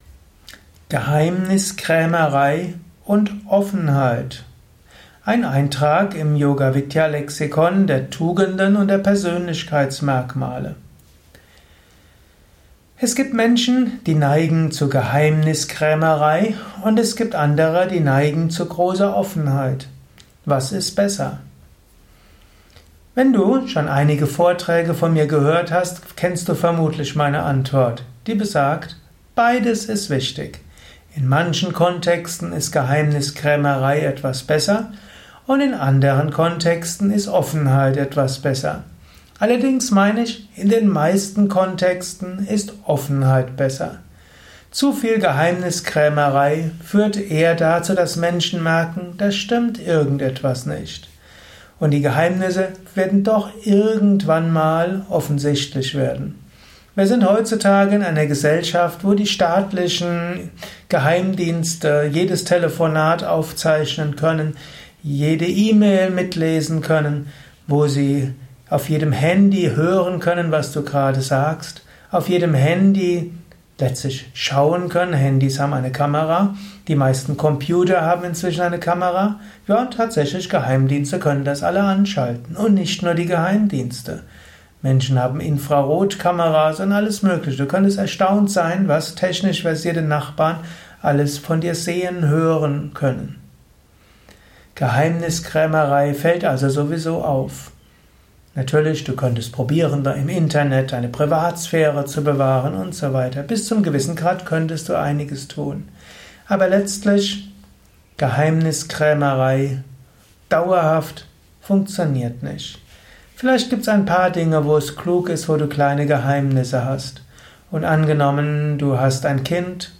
Geheimniskrämerei und Offenheit (Yoga Psychologie Vortrag Podcast)